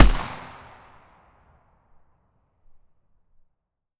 AR2_Shoot 01.wav